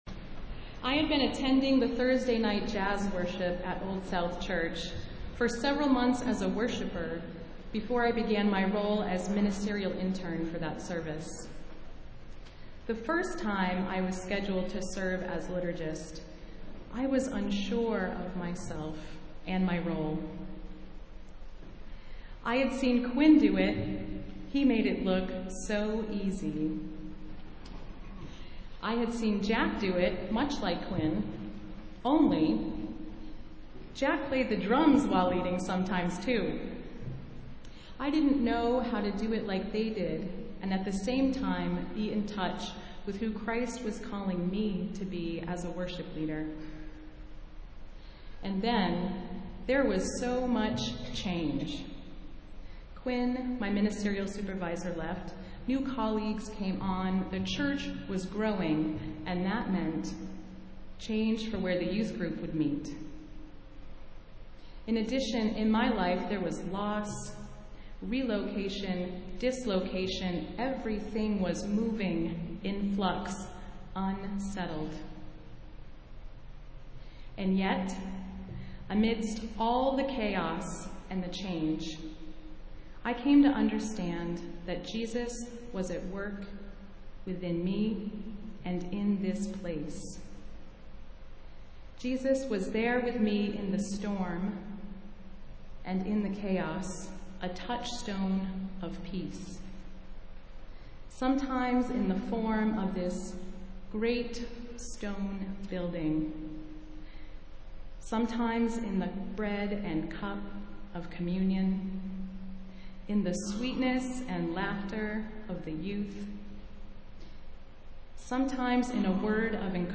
Festival Worship - Fourth Sunday after Pentecost